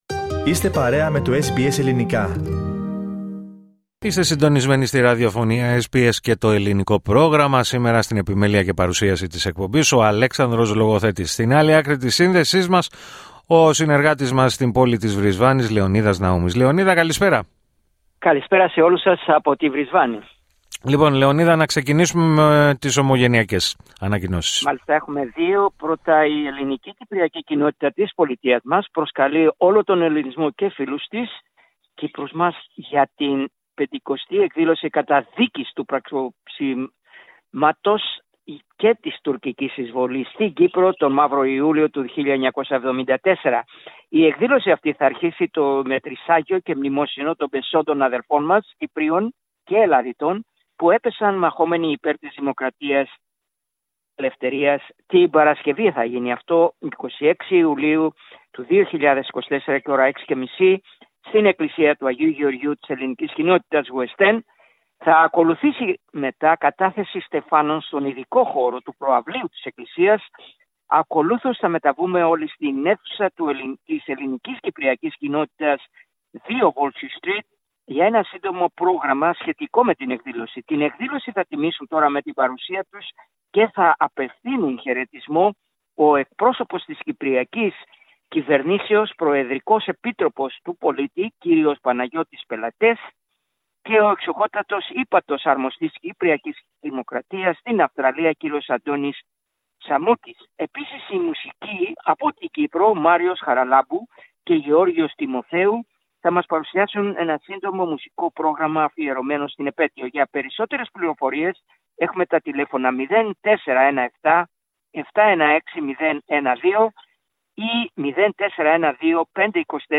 Ακούστε την εβδομαδιαία ανταπόκριση από την Βρισβάνη